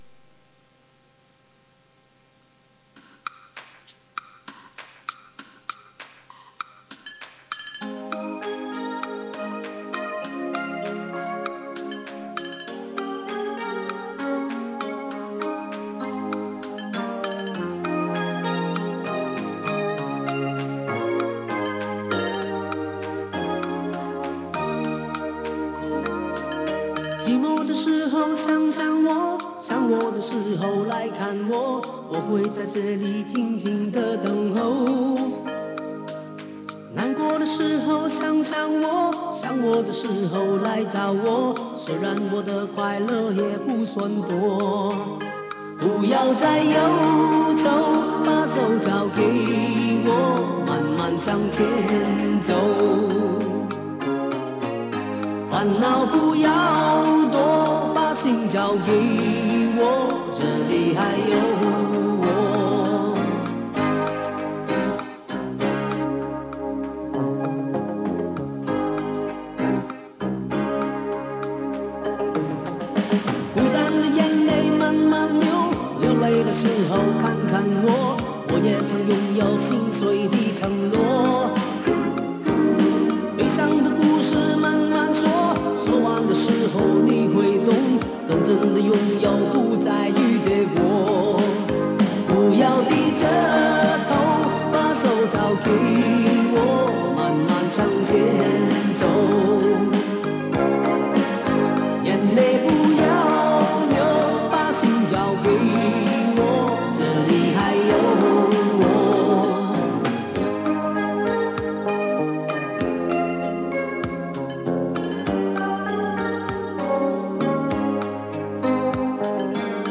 磁带数字化：2022-07-09